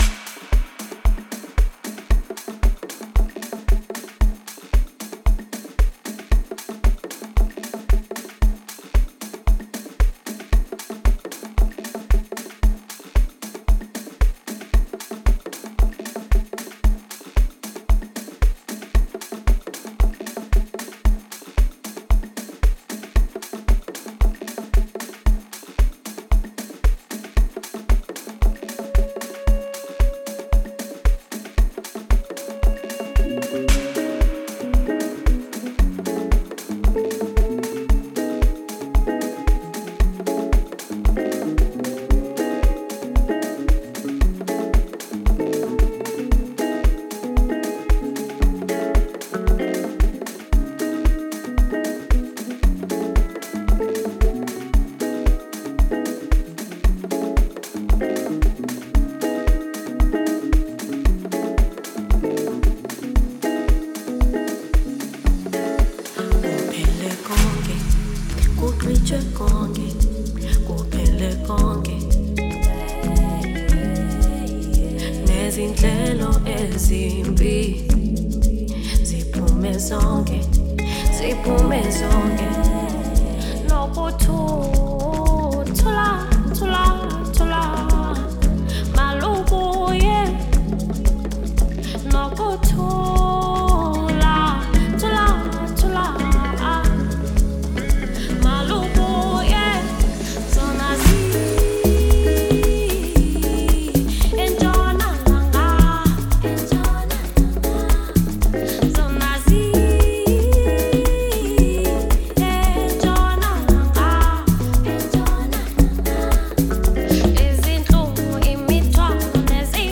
Amapiano-infused